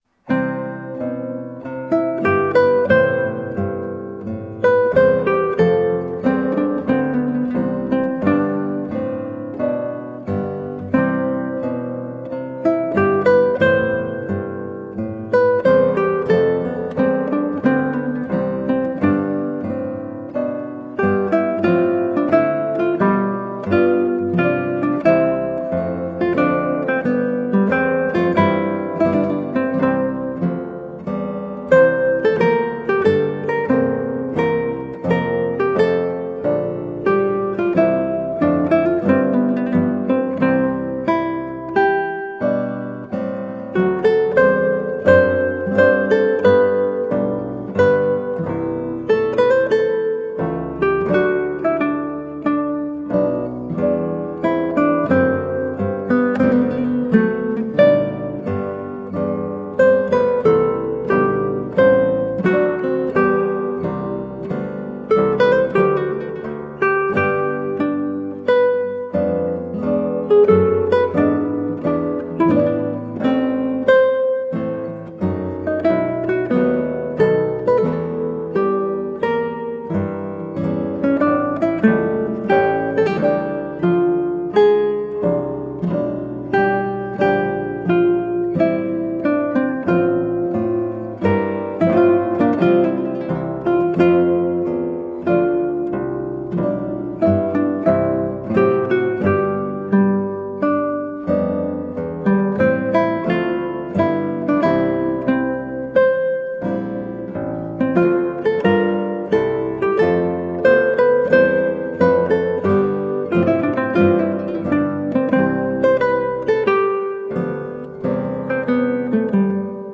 Colorful classical guitar.
Classical, Baroque, Instrumental